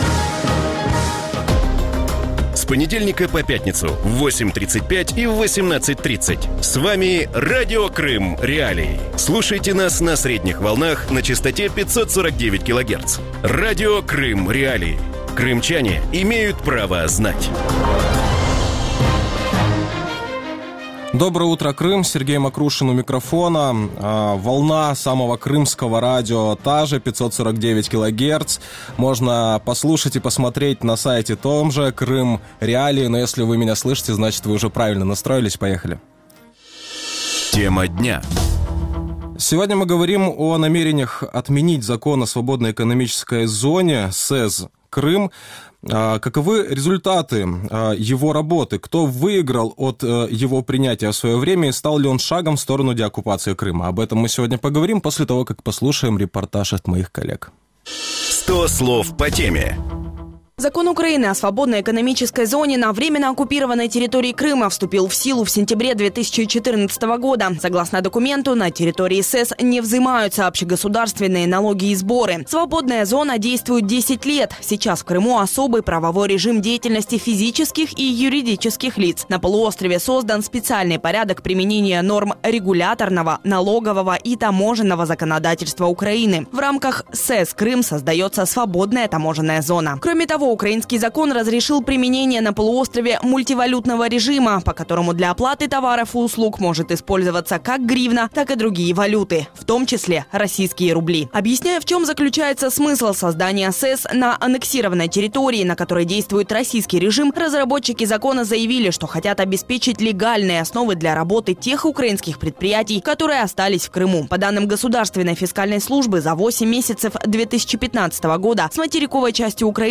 Закон про вільну економічну зону «Крим», який ухвалювали у вересні 2014 року, вже застарів і потребує змін. Таку думку в ранковому ефірі Радіо Крим.Реалії висловив заступник міністра з питань тимчасово окупованих територій Юсуф Куркчі.